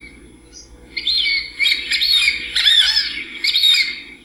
Es ruidoso y emite un corto silbido.